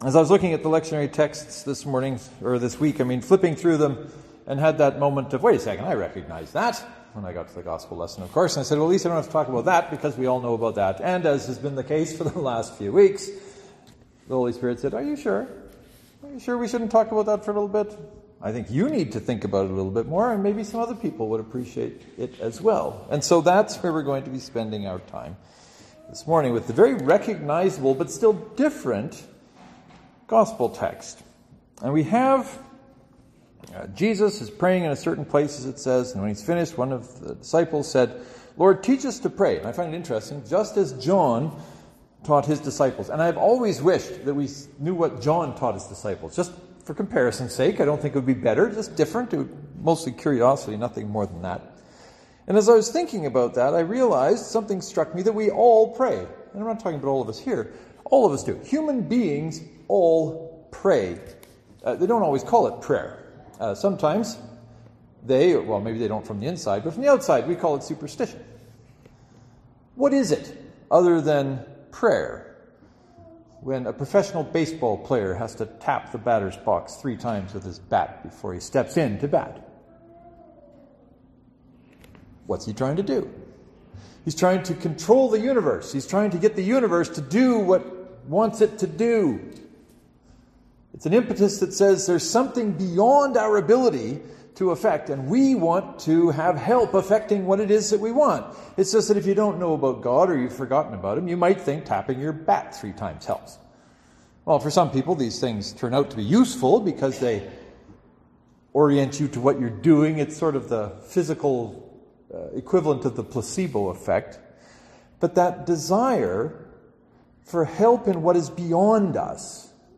“I recognize that” Knox Presbyterian (to download, right click and select “Save Link As . . .”)